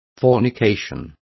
Complete with pronunciation of the translation of fornication.